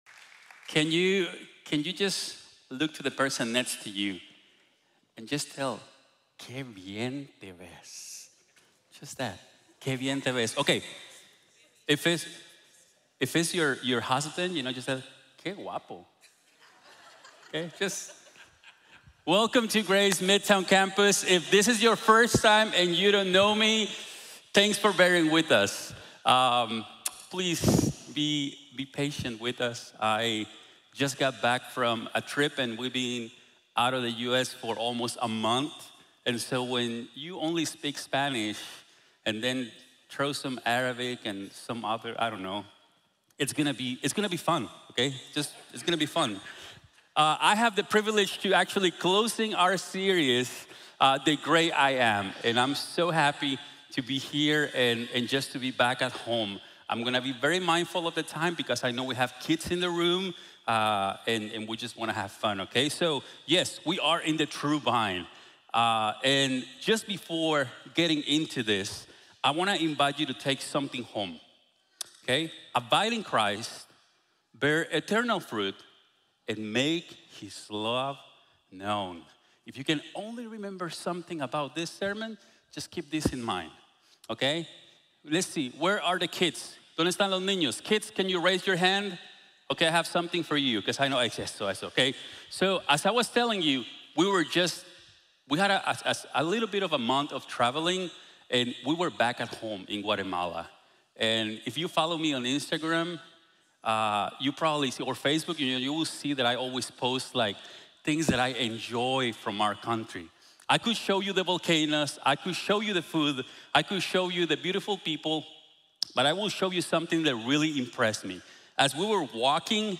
Yo soy la vid verdadera | Sermón | Iglesia Bíblica de la Gracia